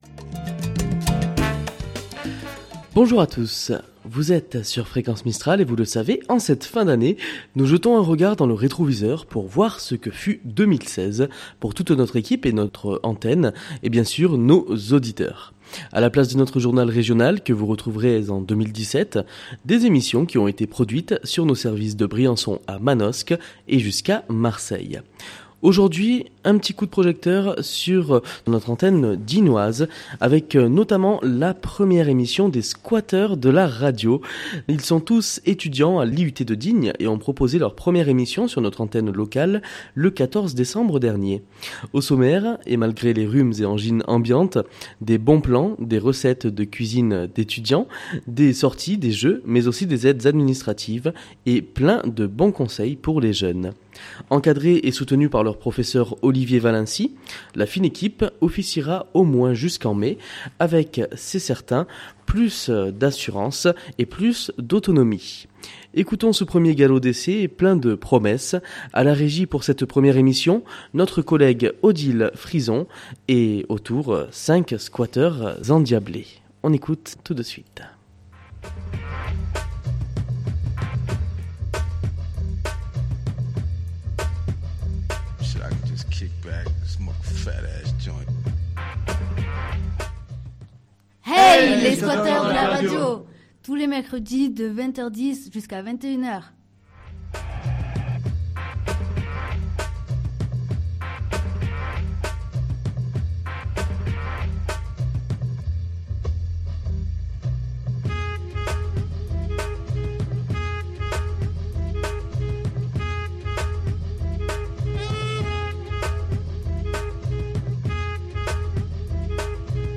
Pendant cette période de fêtes Fréquence Mistral vous propose des rediffusions d'émissions qui auront marqué l'année qui s'achève.